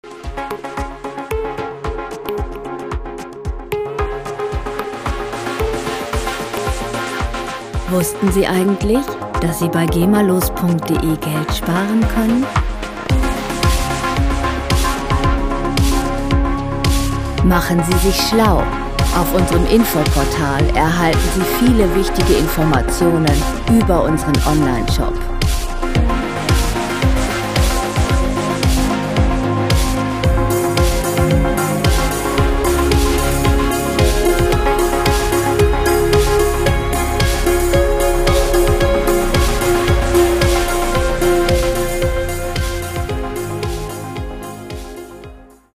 Elektronische Musik - Moderne Welt
Musikstil: Electro
Tempo: 112 bpm
Tonart: Cis-Moll
Charakter: offen, nachdenklich
Instrumentierung: Synthesizer